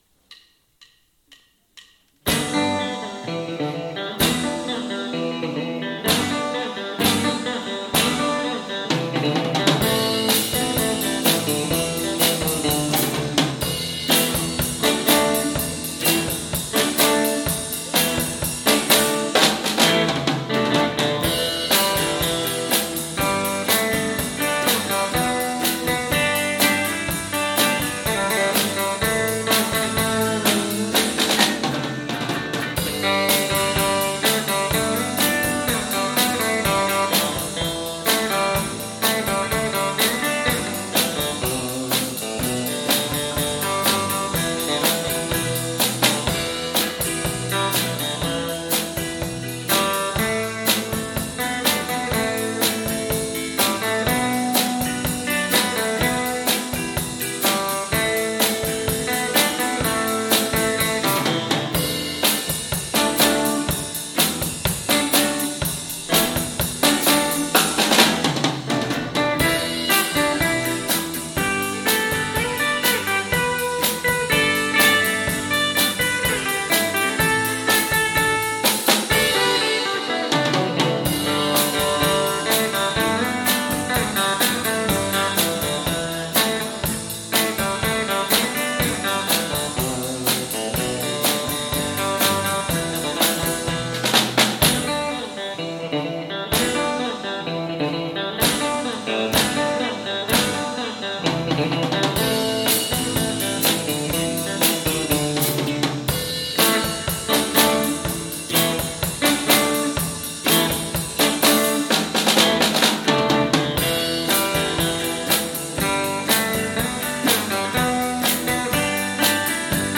桂病院土曜コンサート-2『おこさ節』...